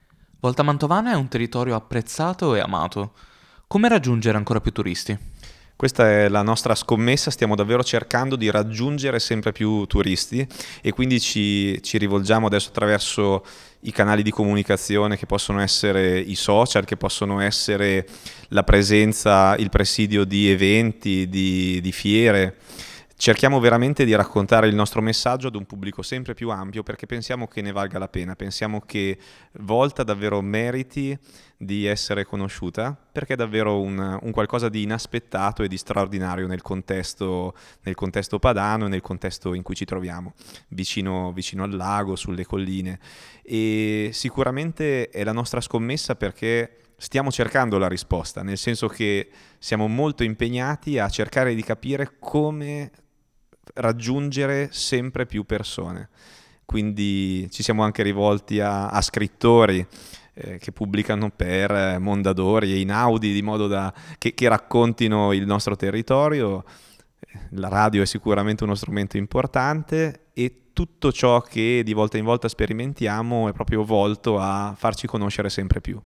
Ecco, ai nostri microfoni, le dichiarazioni raccolte durante la serata:
Alberto Bertini, assessore alla cultura di Volta Mantovana